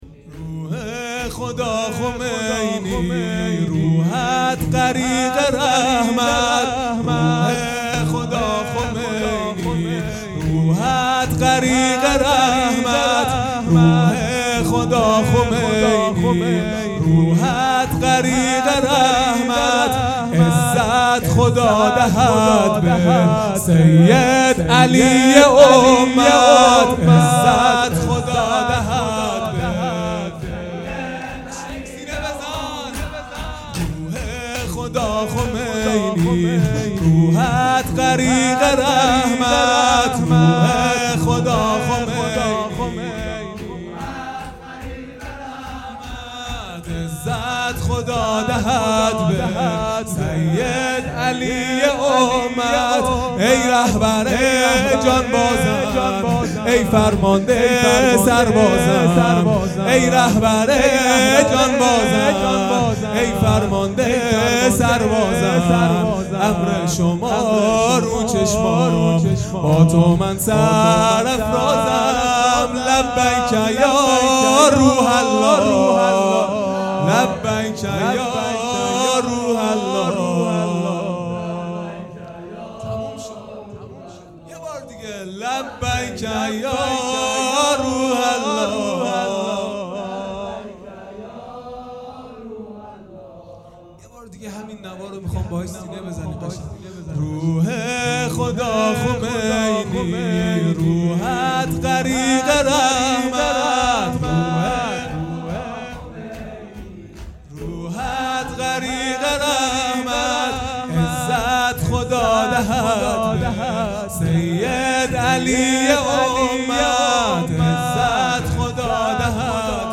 شور | روح خدا خمینی روحت غریق رحمت
جلسه هفتگی | به مناسبت رحلت امام خمینی و قیام ۱۵ خرداد | ۱۵ خرداد ۱۳۹۹